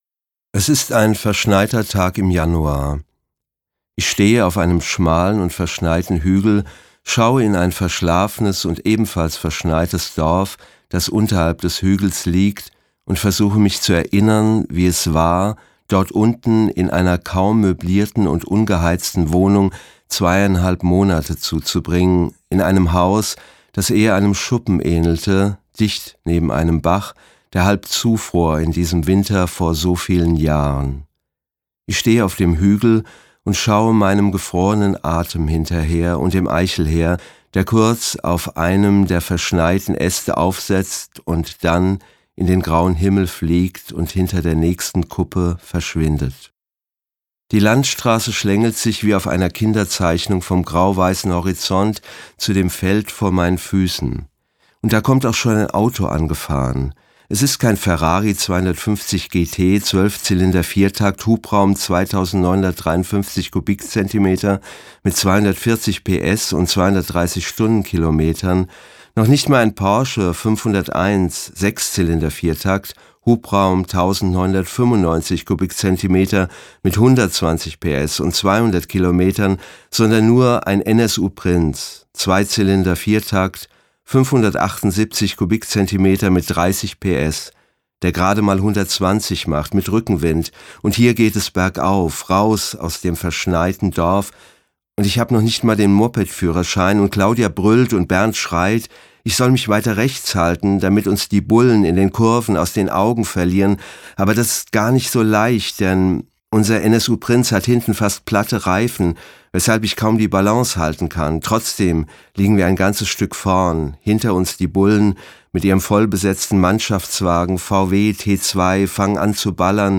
Frank Witzel (Sprecher)
Schlagworte Ausgezeichnet • Buchpreis • Bundesrepublik Deutschland (1949-1990); Romane/Erzählungen • Gesellschaft • Hörbuch • Hörbuch; Literaturlesung • Jugend • Lesung • Literatur • RAF • RAF (Rote Armee Fraktion); Roman/Erzählung